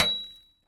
Tap5.mp3